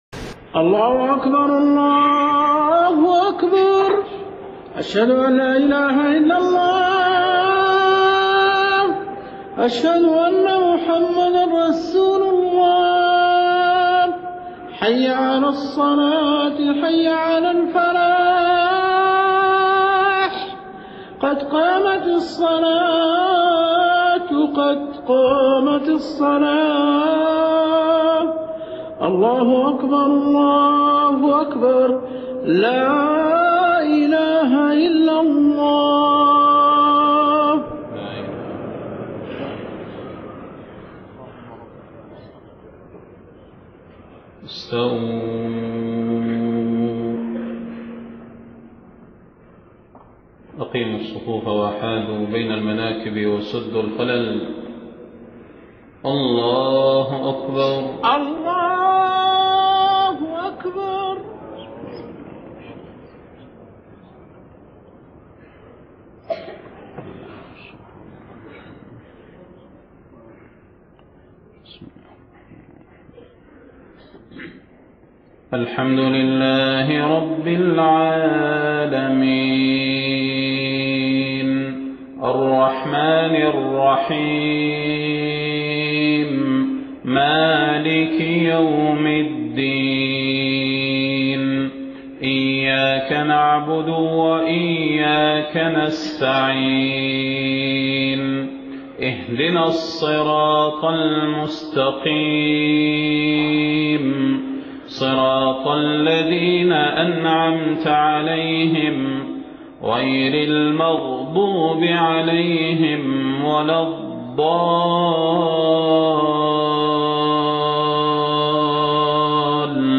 صلاة الفجر25 محرم 1430هـ سورة الذاريات كاملة > 1430 🕌 > الفروض - تلاوات الحرمين